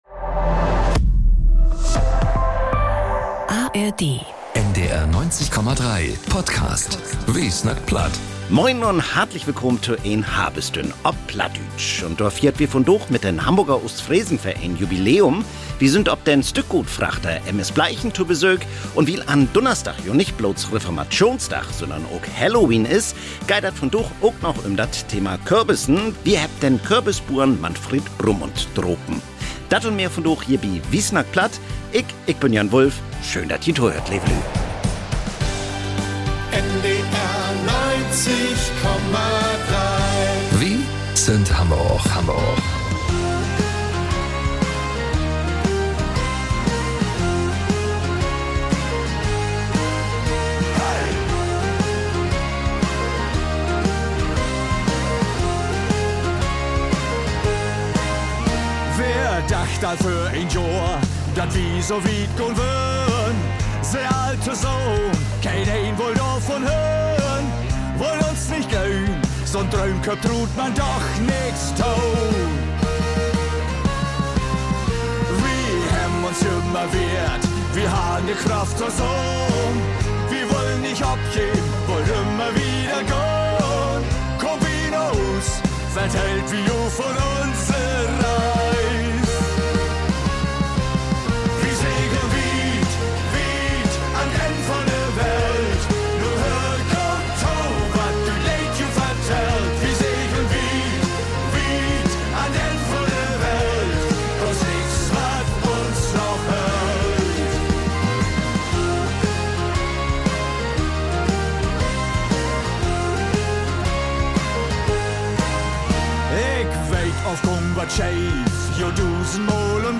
Nachrichten - 07.01.2025